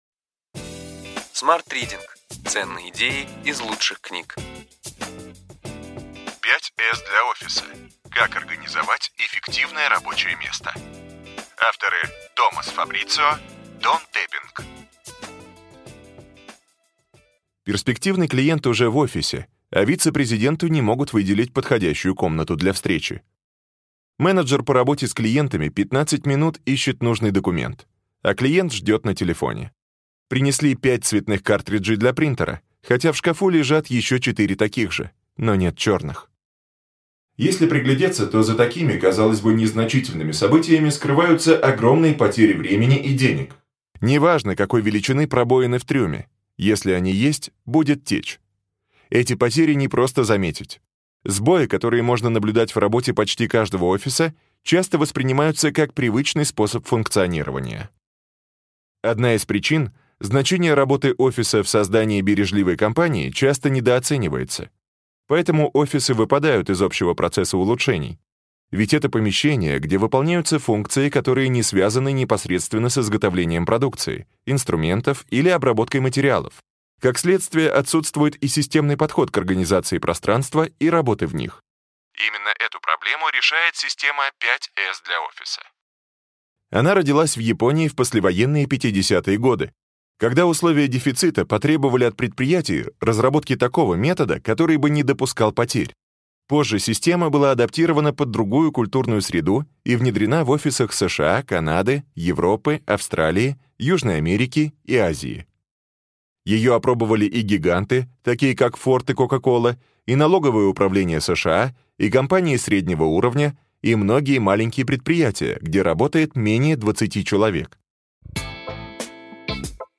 Аудиокнига Ключевые идеи книги: 5S для офиса. Как организовать эффективное рабочее место.